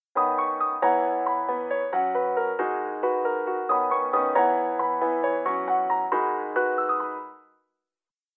最後にBPFと通すと
わぁ、これはなんか懐かしい感じだよっ！あれだねっ、ローファイな感じっ。